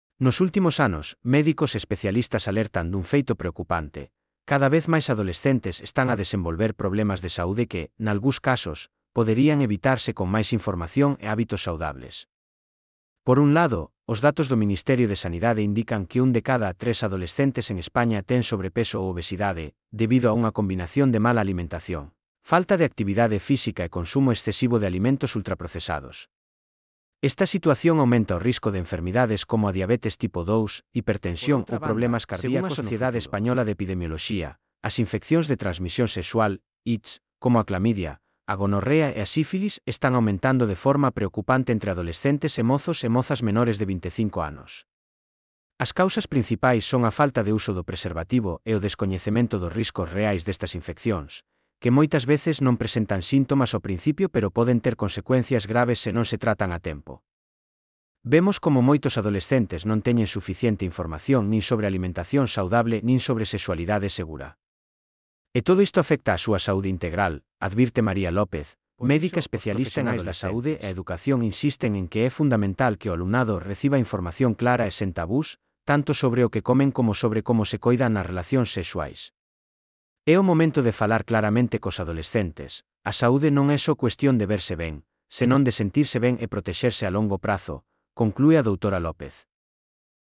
Transcrición de texto a audio
Elaboración propia coa ferramenta Narakeet (CC BY-SA)
Noticia do xornal para analizar
ODE6-lecturanoticiaactividadepuntuable.mp3